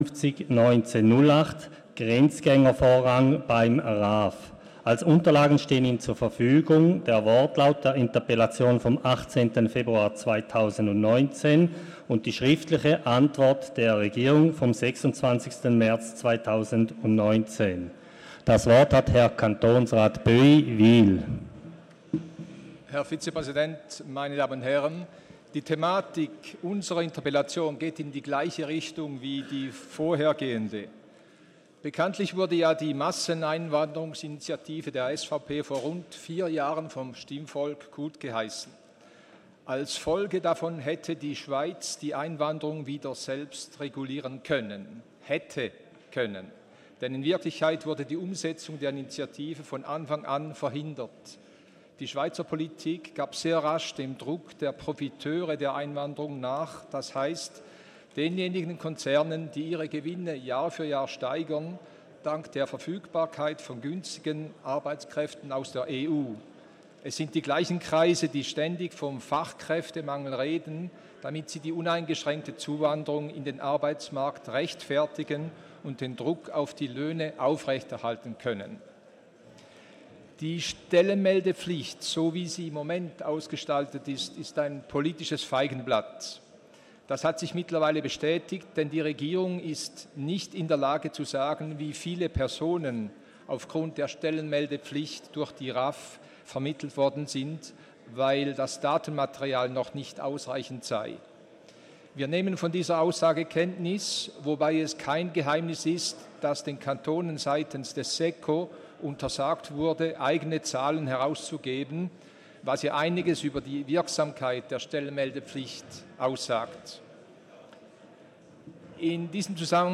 12.6.2019Wortmeldung
Session des Kantonsrates vom 11. bis 13. Juni 2019